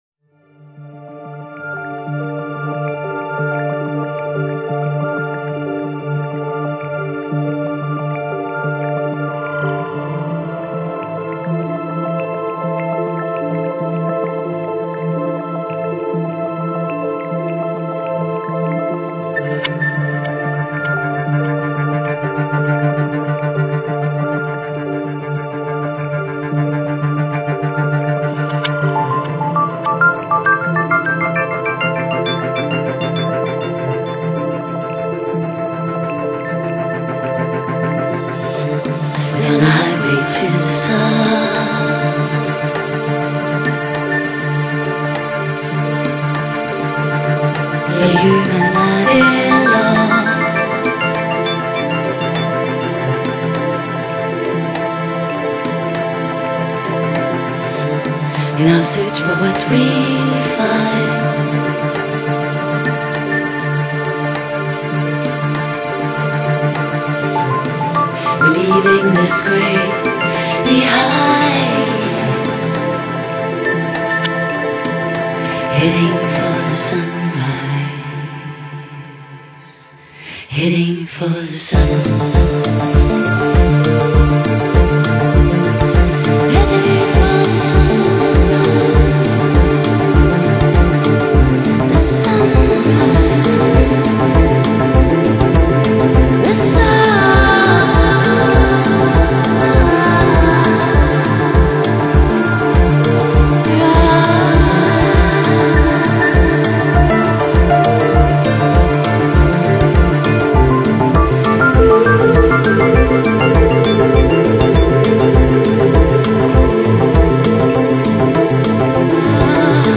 唱片风格：Chillout